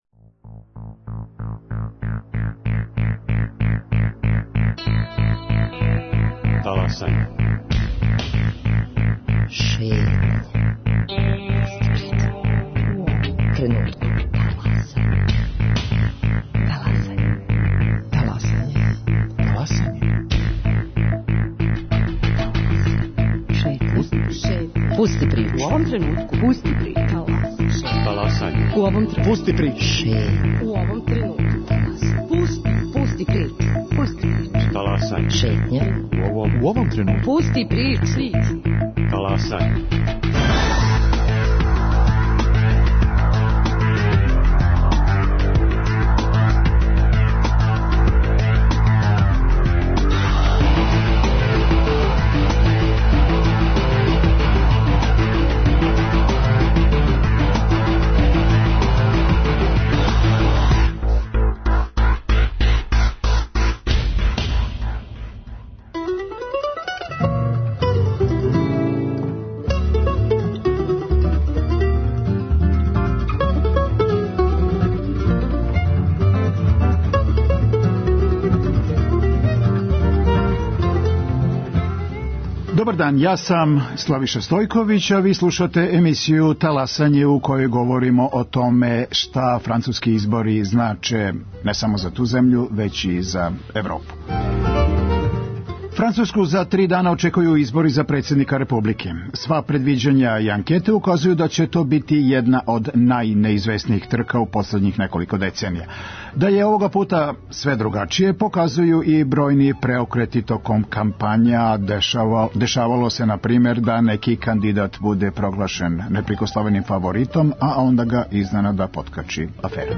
Чућемо и актере изборне кампање.